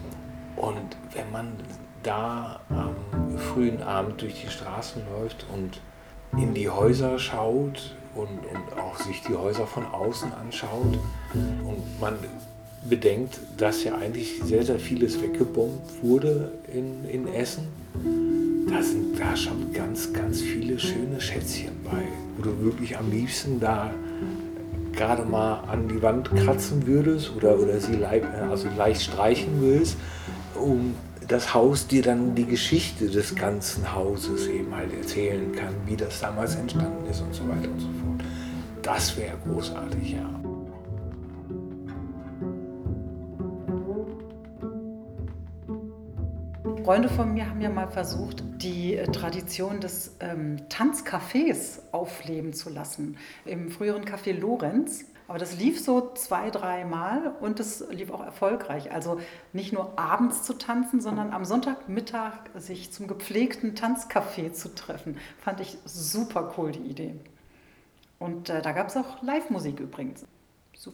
Gitarre
Kontrabass
Percussion